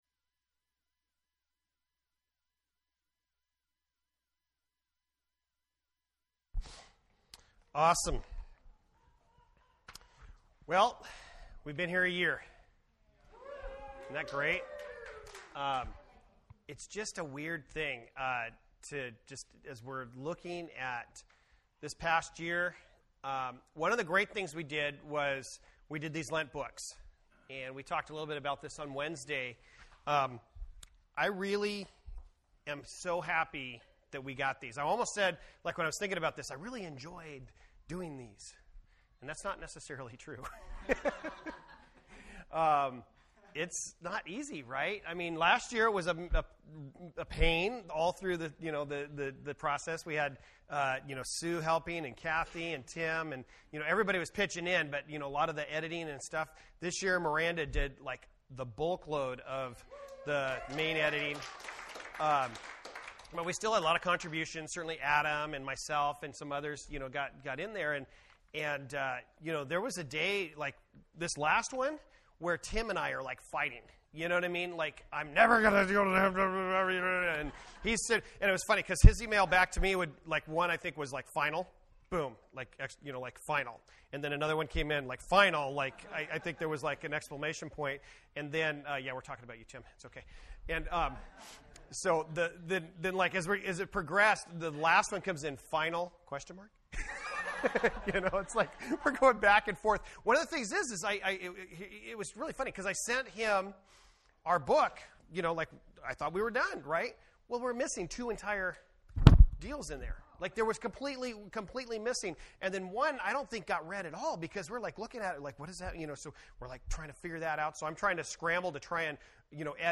FWC Sermons Service Type: Sunday Morning %todo_render% Related « Put Away Childish Things